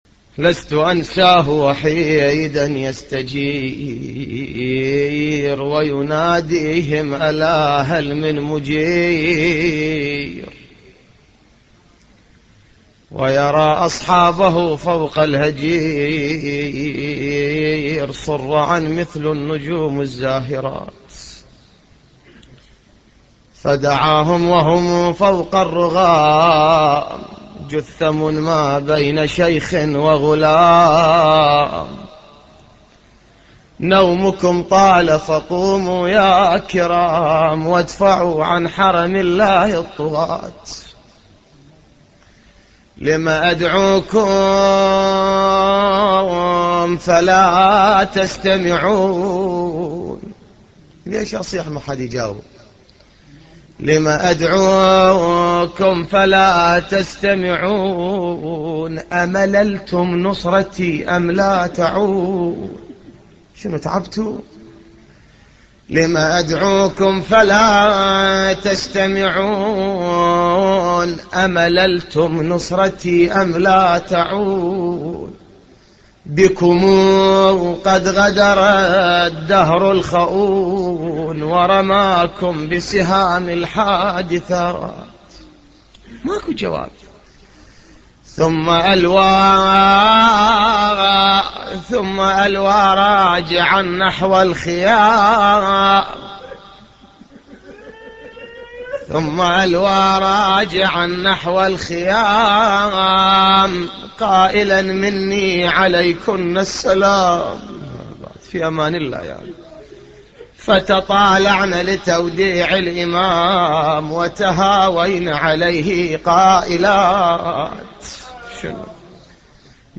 نواعي حسينية 8